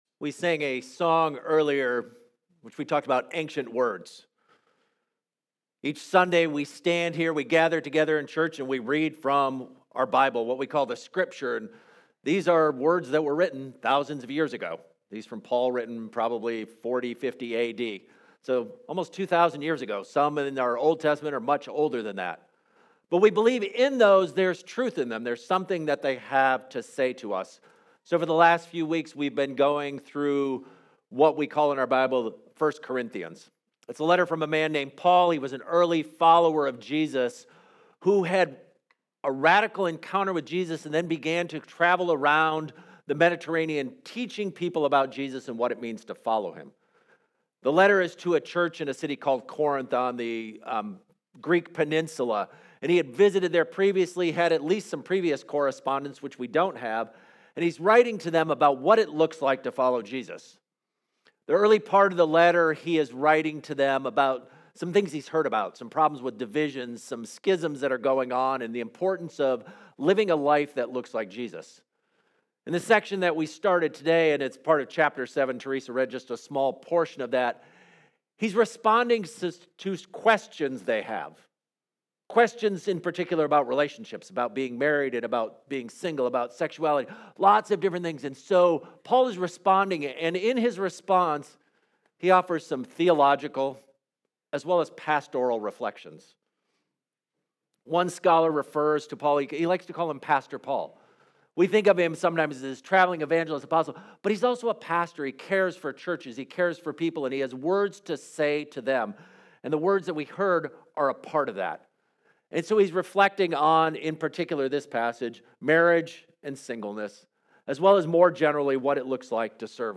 This sermon explores Paul's pastoral and theological wisdom from 1 Corinthians 7 regarding marriage, singleness, and devotion to Christ.